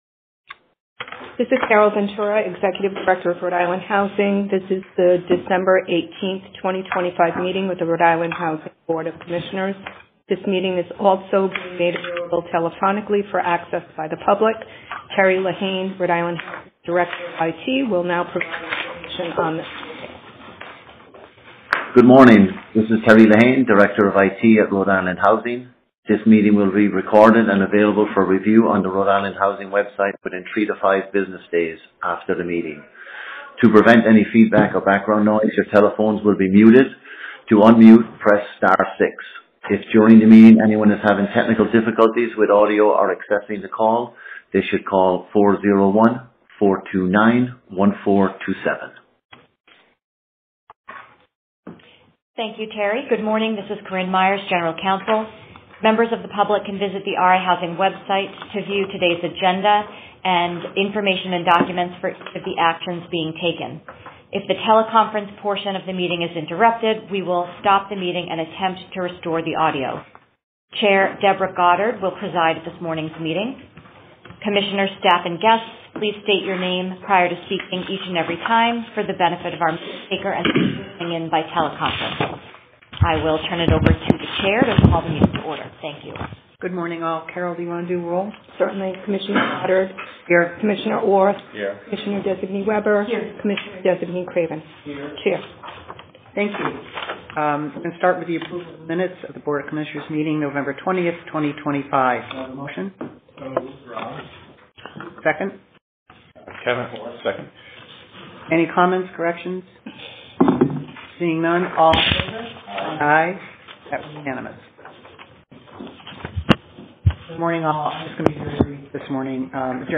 Recording of RIHousing Board of Commissioners Meeting: 12.18.2025